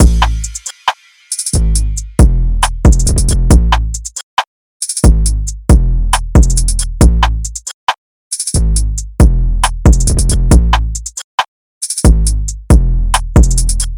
Saturate (137 BPM – Am)
UNISON_DRUMLOOP_Saturate-137-BPM-Am.mp3